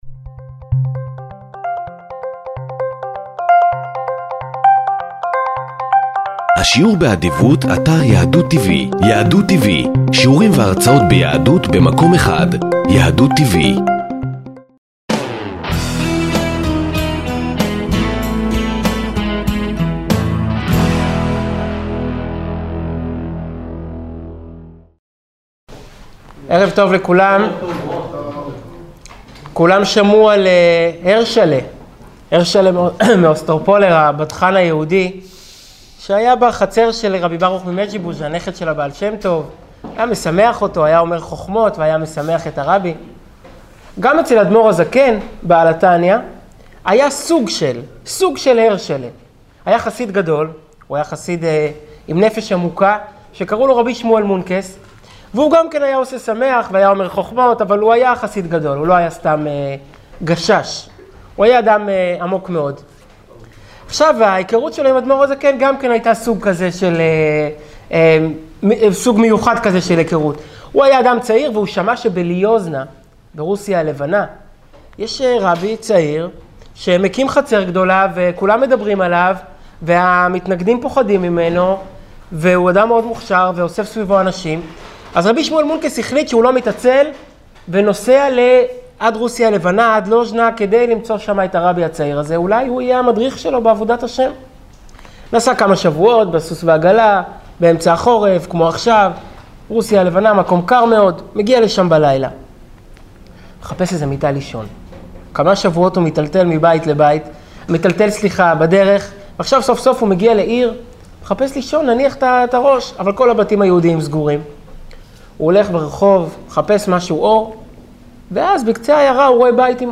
שיעור מרתק לפרשת וישב וי"ט כסלו
שנמסר בביהכנ"ס חב"ד בראשל"צ